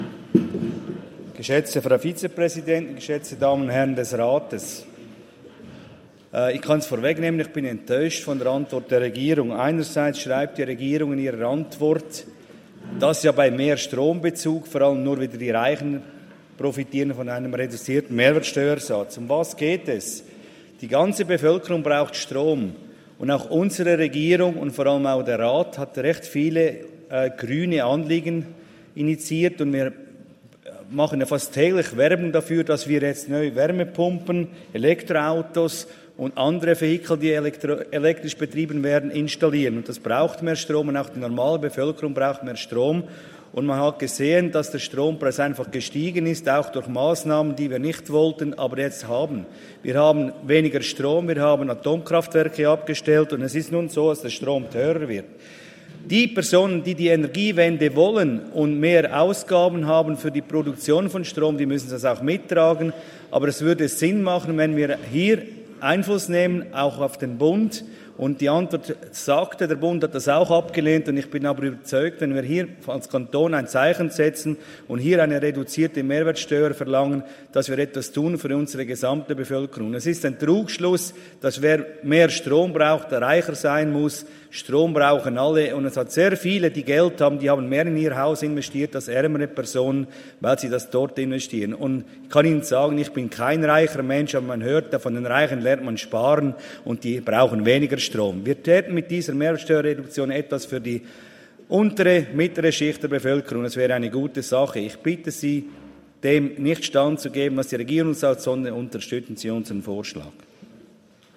Session des Kantonsrates vom 27. bis 29. November 2023, Wintersession
28.11.2023Wortmeldung